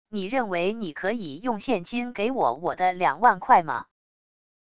你认为你可以用现金给我我的两万块吗wav音频生成系统WAV Audio Player